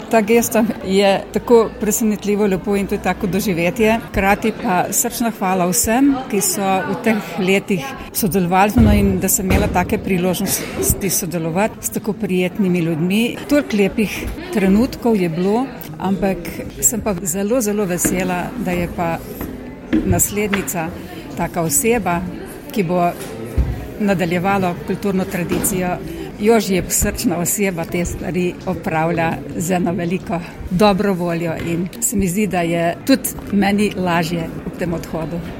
pripeta tonska izjava